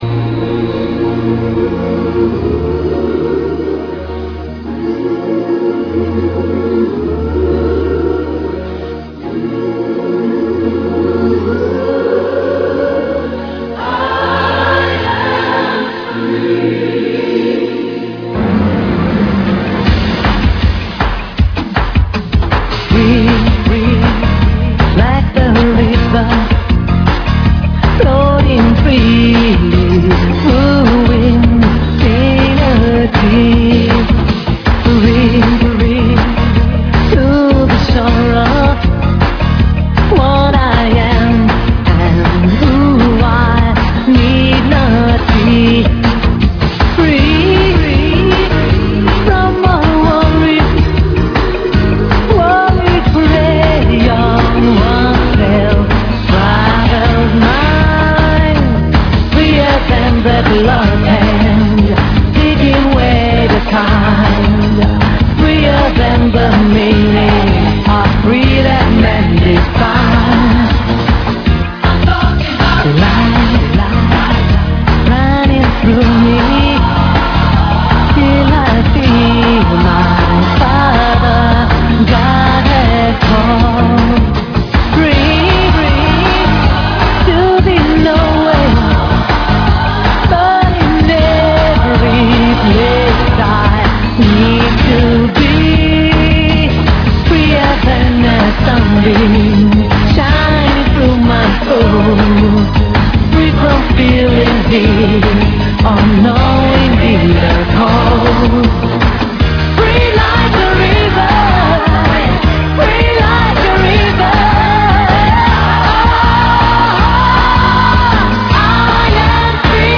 cancion en directo